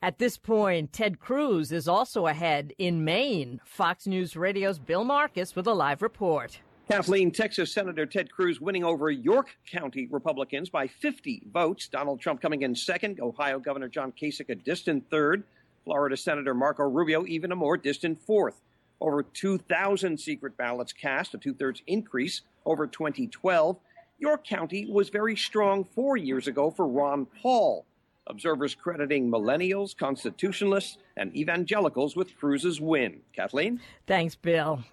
(BIDDEFORD, MAINE) MARCH 5 – LIVE, 8PM –
FOX-NEWS-RADIO-8PM-LIVE-.mp3